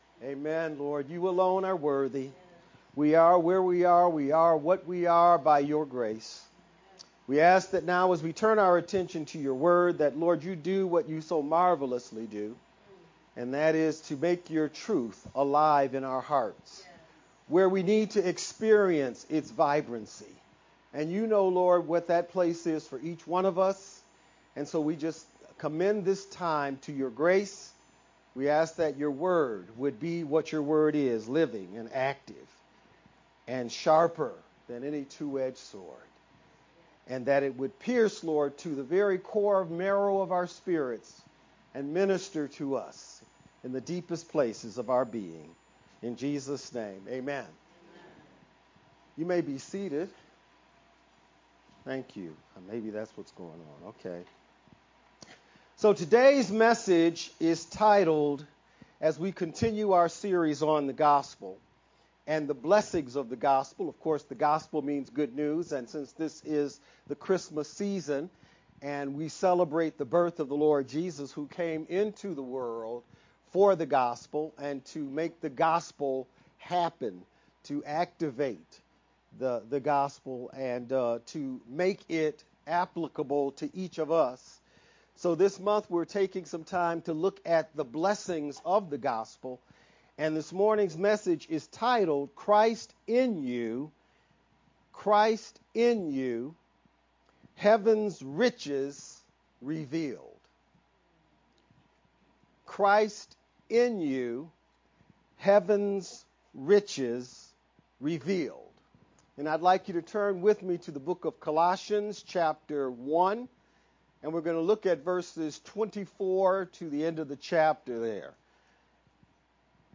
VBCC-edited-sermon-only-12-14_Converted-CD.mp3